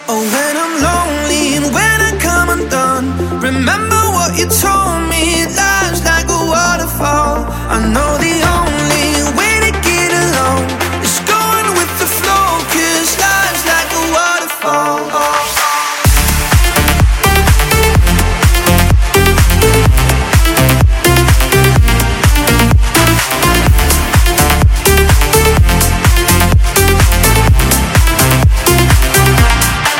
клубные , зажигательные , future house , нарастающие